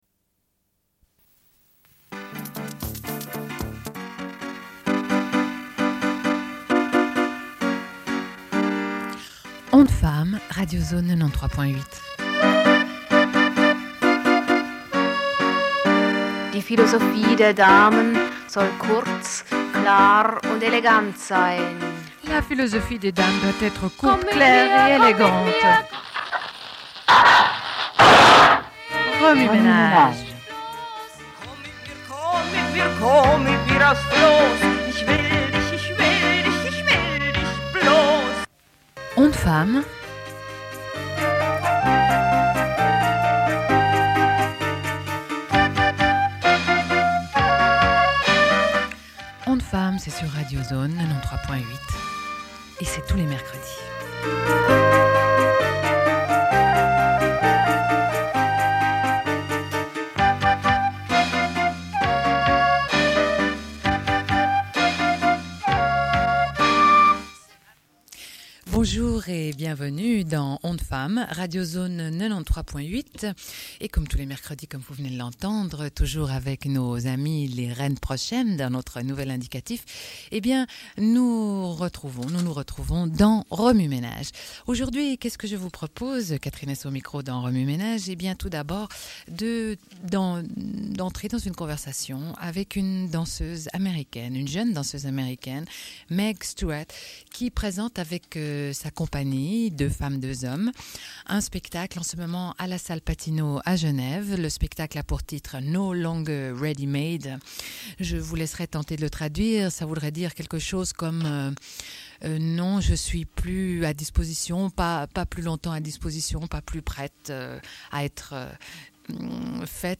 Une cassette audio, face A31:10
Diffusion d'un entretien. Puis sur la poésie populaire de femmes pachtounes en Afghanistan, avec le livre Le suicide et le chant, textes recueillis par Sayd Bahodine Majrouh.